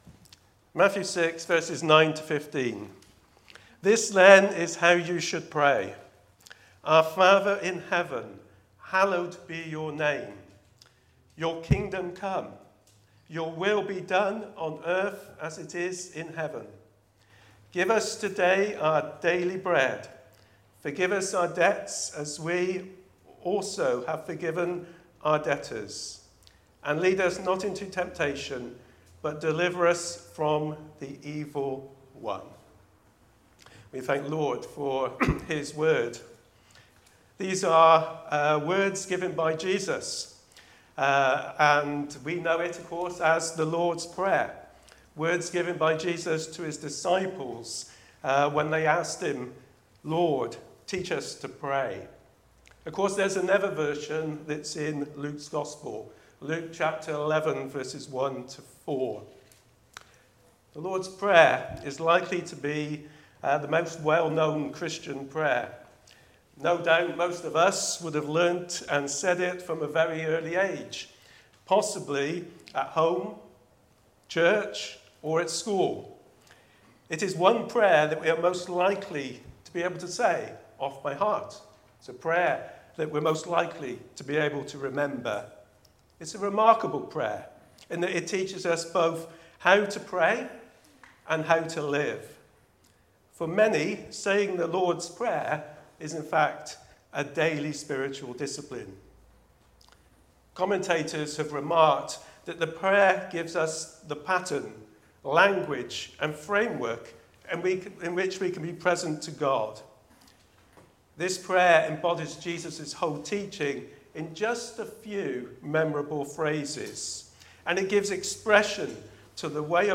Reflection: The Lord’s prayer